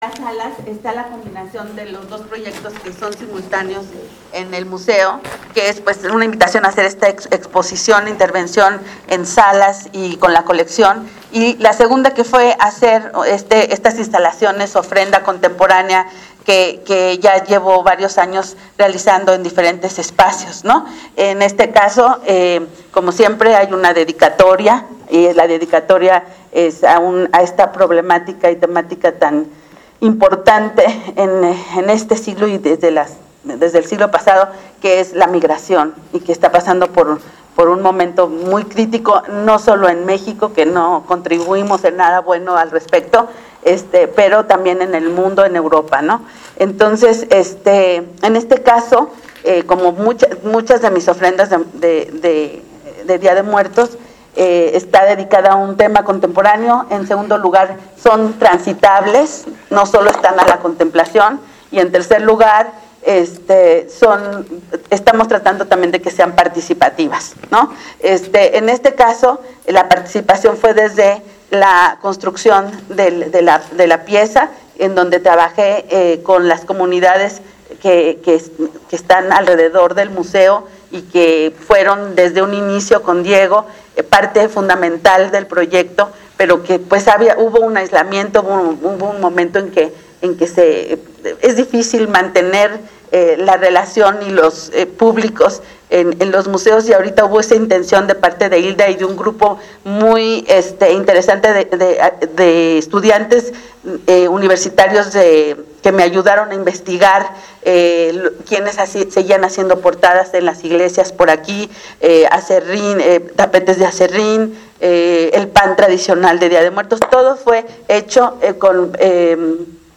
betsabee_romero__artista_plastica.mp3